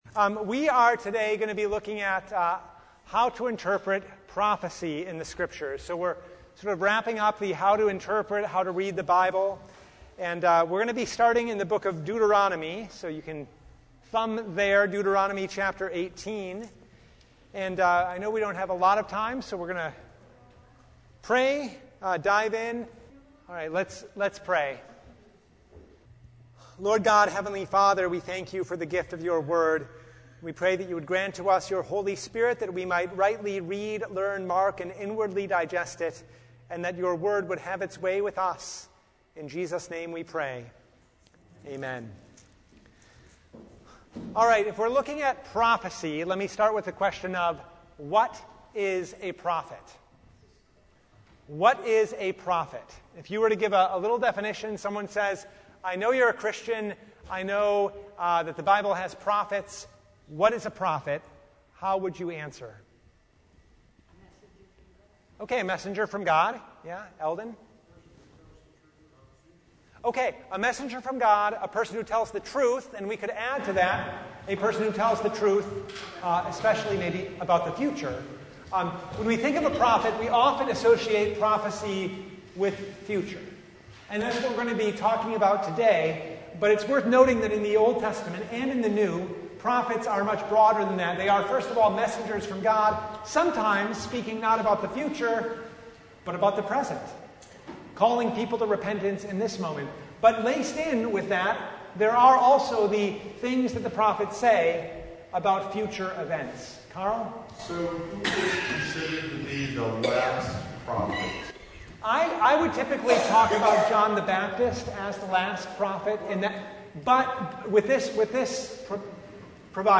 Deuteronomy Service Type: Bible Study Topics